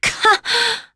Nicky-Vox-Deny2.wav